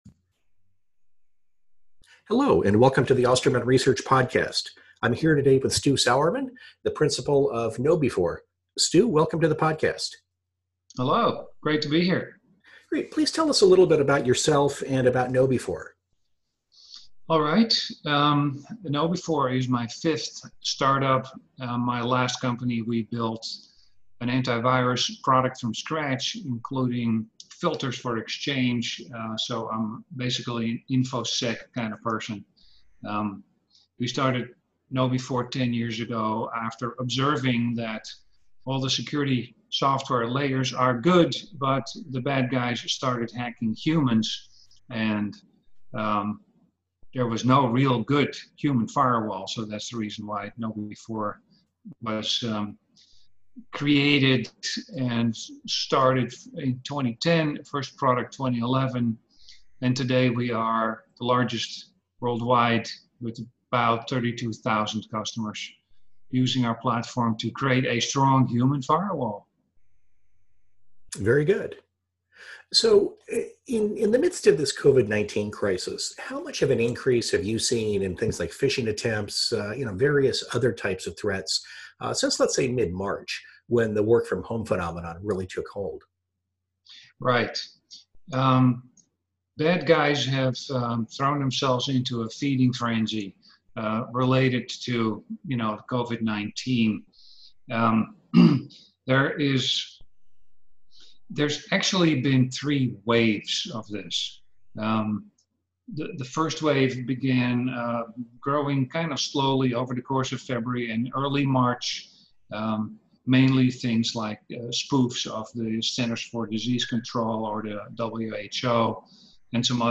A Conversation About New Security Threats Arising from the COVID-19 Crisis